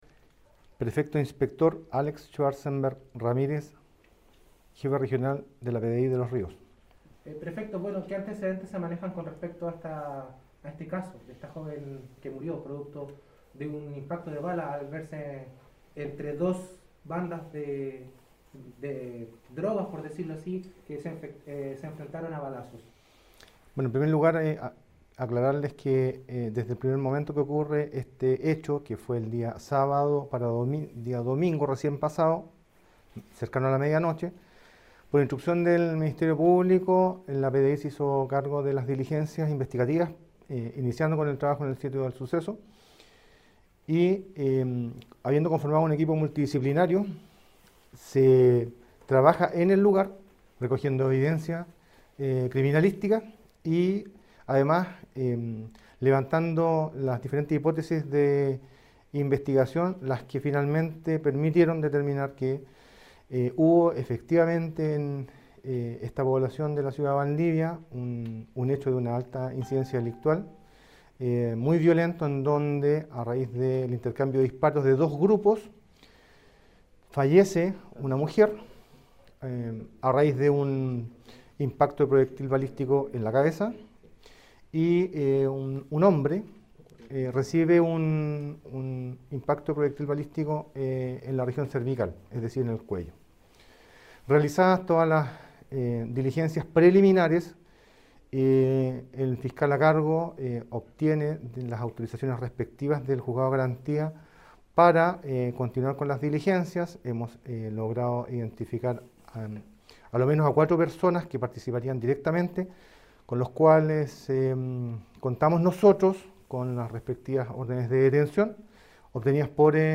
Cuña del Prefecto Inspector Alex Schwarzenberg Ramirez, Jefe regional de la PDI en Los Ríos.
PREFECTO-INSPECTOR-ALEX-SCHWARZENBERG-RAMIREZ-JEFE-REGIONAL-PDI.mp3